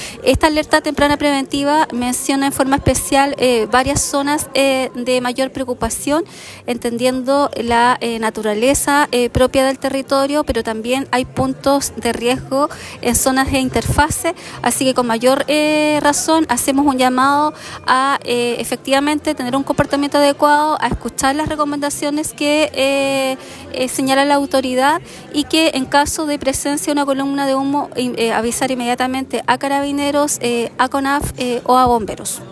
Esta medida aplica especialmente en sectores de diversas comunas de la provincia, donde un incendio forestal podría poner en riesgo a viviendas cercanas. Por ello, la autoridad hizo un llamado a la ciudadanía a estar alerta y, ante cualquier evidencia de fuego o humo, contactarse de inmediato con CONAF o Bomberos.